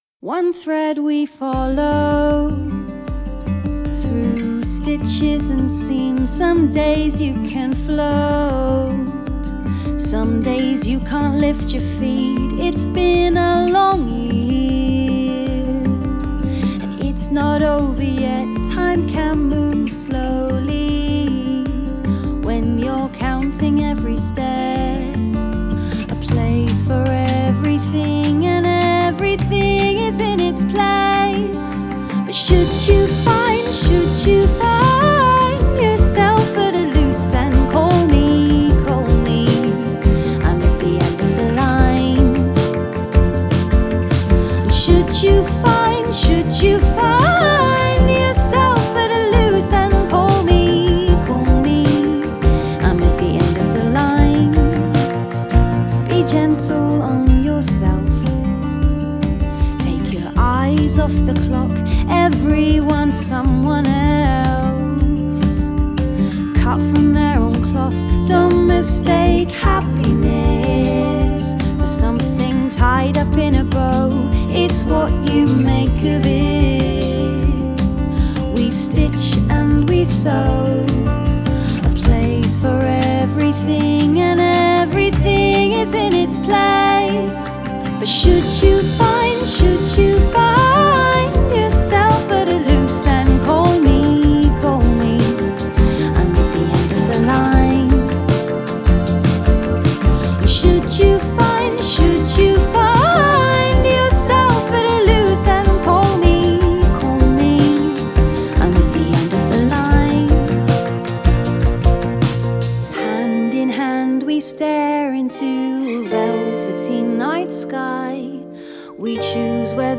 L'émotion toujours à fleur de peau